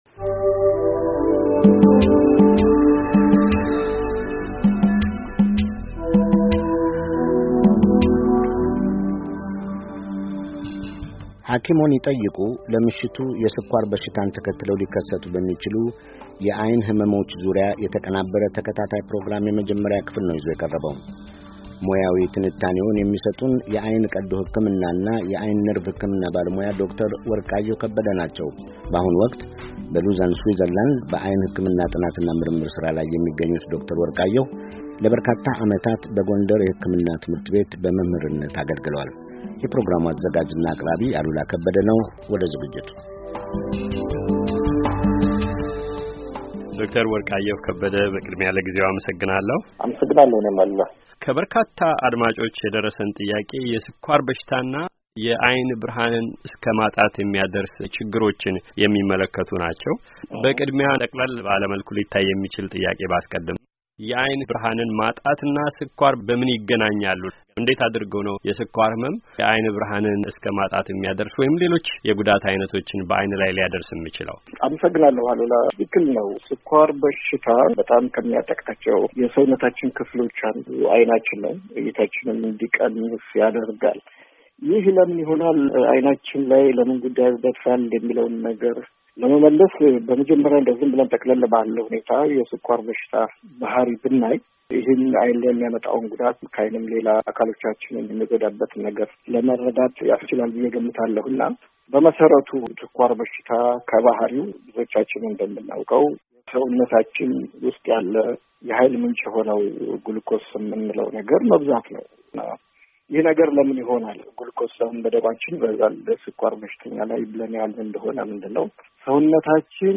ሞያዊ ትንታኔውን የሚሰጡን የዓይን ቀዶ ሕክምናና የዓይን ነርቭ ሕክምና ባለሞያው